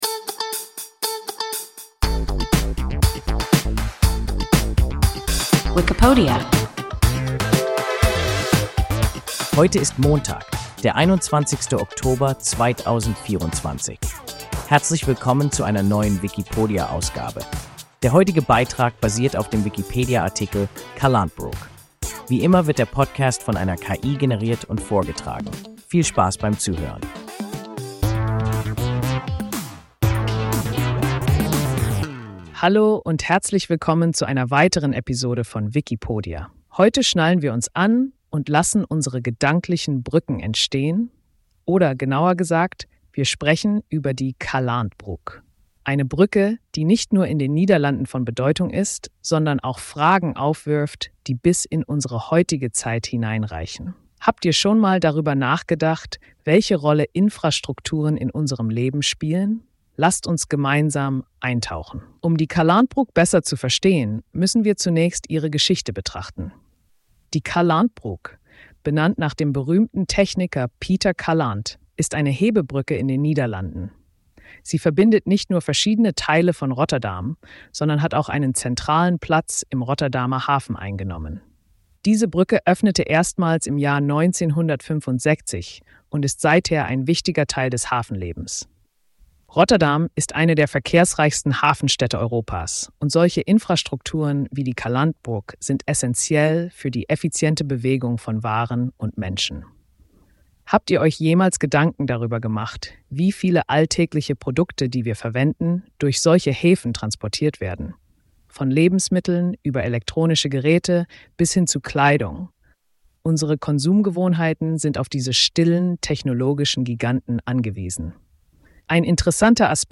Calandbrug – WIKIPODIA – ein KI Podcast